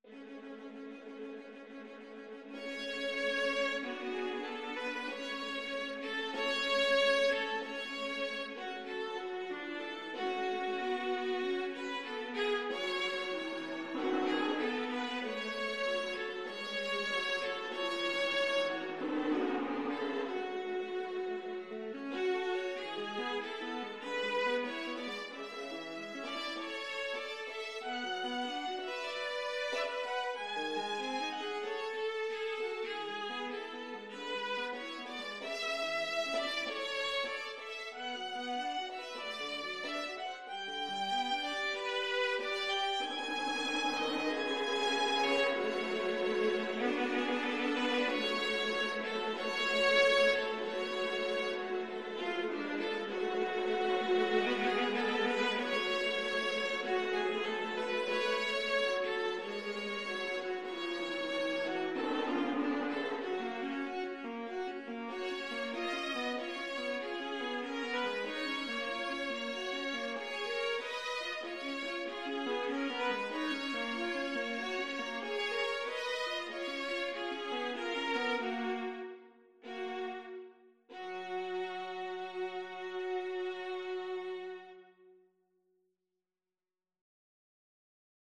Free Sheet music for Viola Duet
Viola 1Viola 2
F# minor (Sounding Pitch) (View more F# minor Music for Viola Duet )
Andante = 95
4/4 (View more 4/4 Music)
Classical (View more Classical Viola Duet Music)